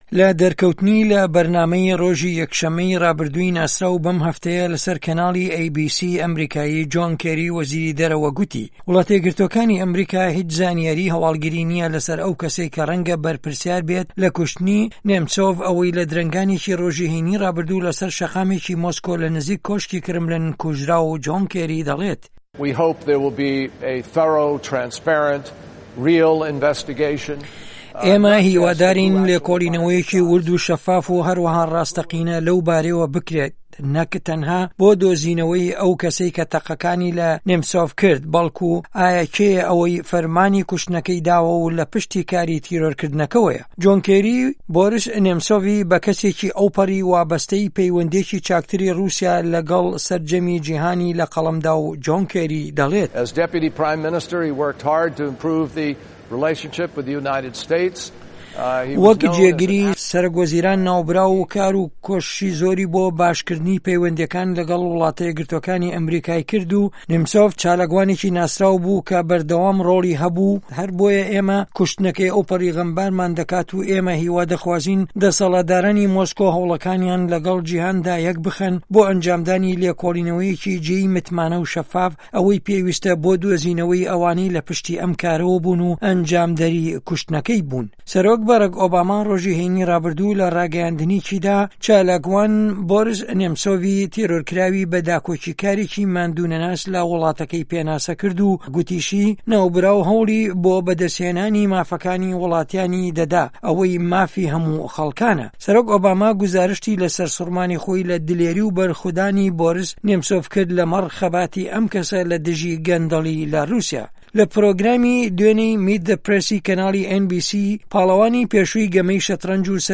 درێژه‌ی ئه‌م راپۆرته‌ له‌م فایله‌ ده‌نگیه‌دا ده‌بیستیت.